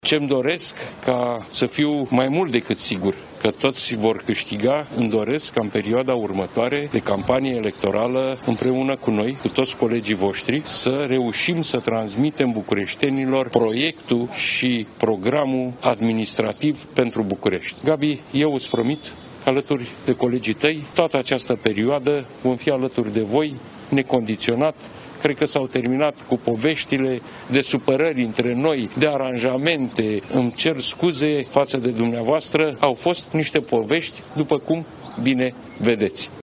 Gabriela Firea și-a depus candidatura pentru un nou mandat la Primăria Capitalei.